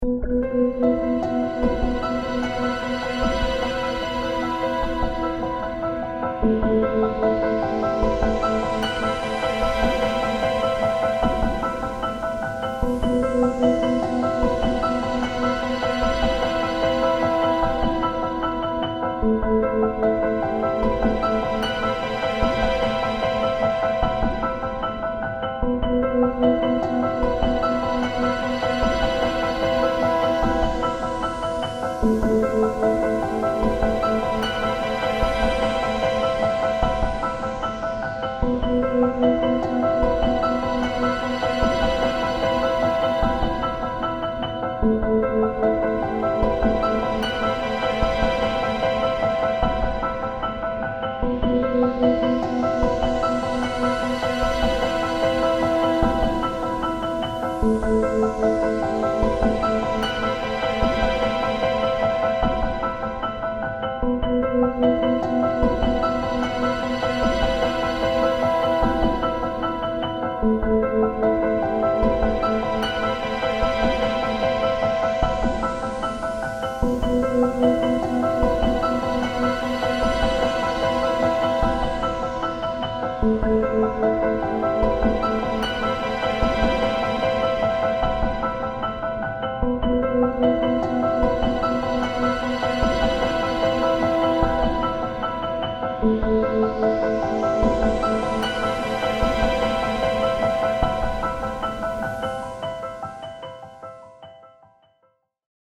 不気味さを感じる静かな曲です。